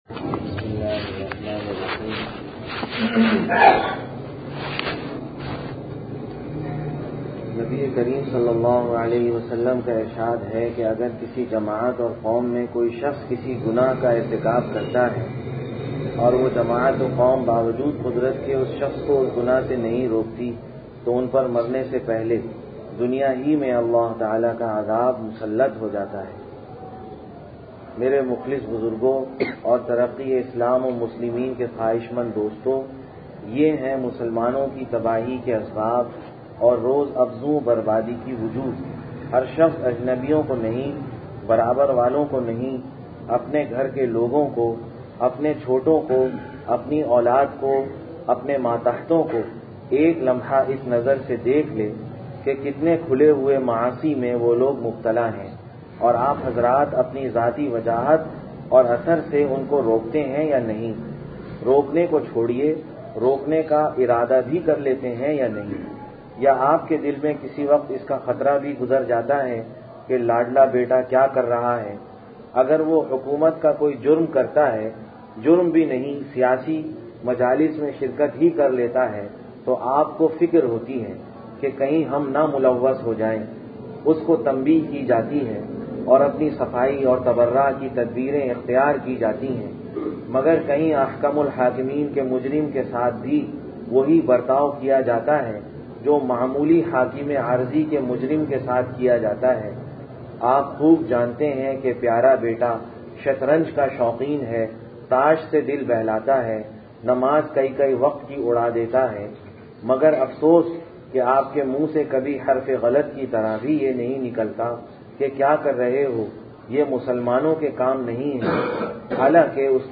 Taleem After Juma Bayan (Mirpurkhas)